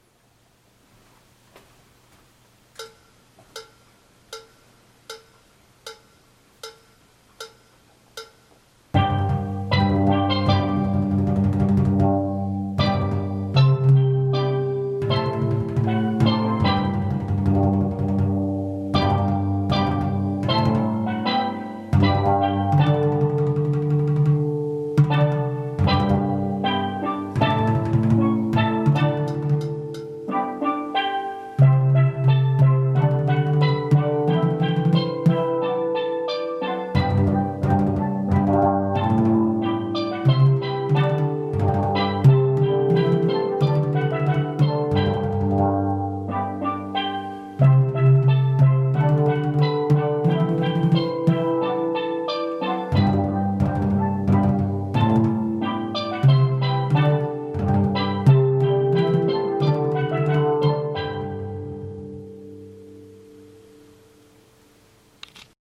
Basse Come Again Vid 78 90 .mp3